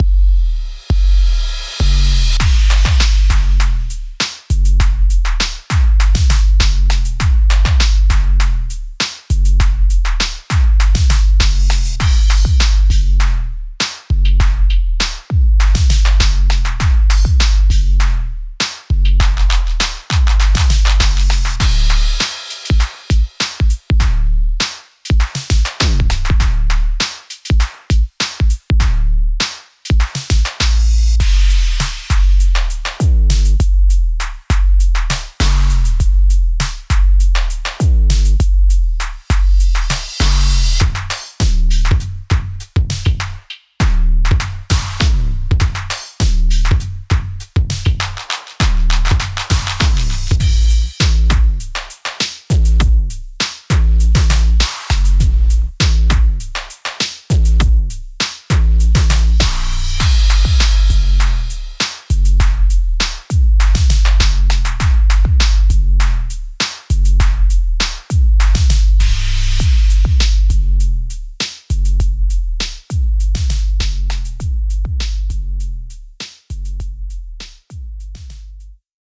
Get that Analog Sound and Vibe with 30 High Quality Drum Sound one shots.
Booming 808’s, Punchy Kicks, Snappy Snares, Crispy Claps, Sizzling Hats and Cymbals!
Everybody needs fresh and modern sounds, so we created the perfect drum sample pack with Normal, Saturated and Distorted sounds.
Demo-Fresh-Funk-Audio-Analog-Drums-v1-192kbps.mp3